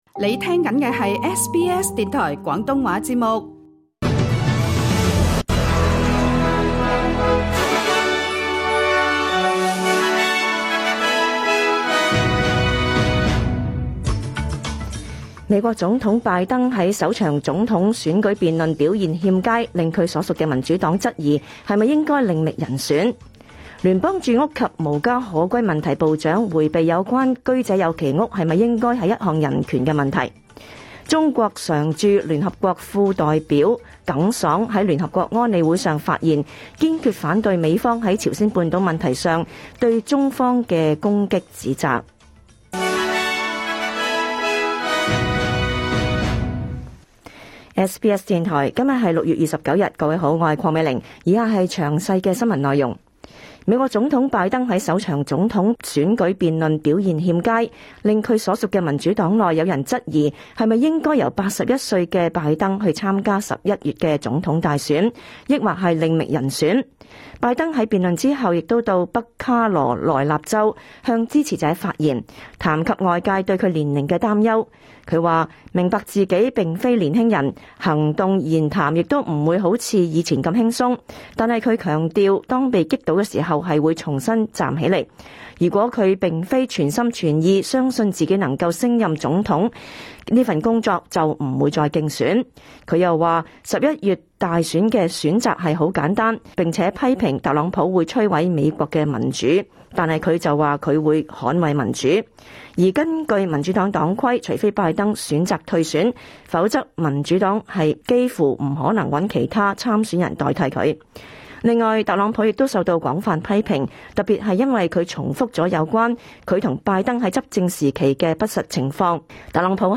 2024 年 6月 29 日 SBS 廣東話節目詳盡早晨新聞報道。